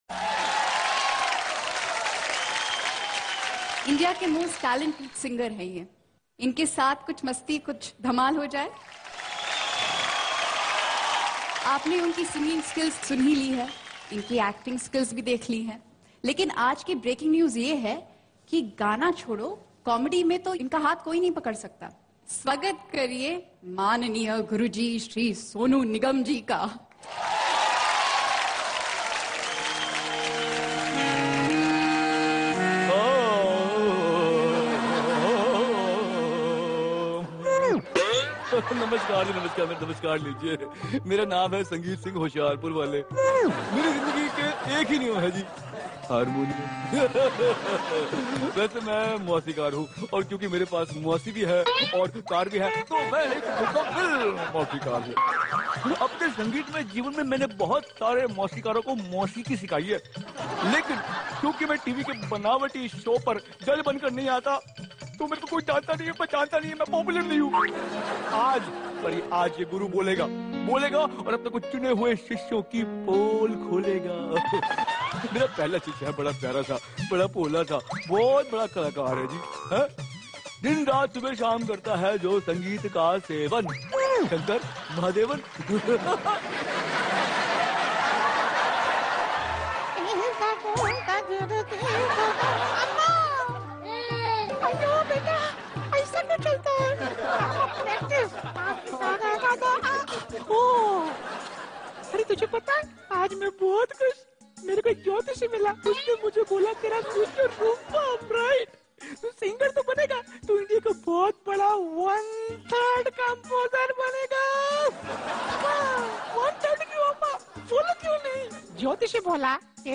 Funny Mimicry Voice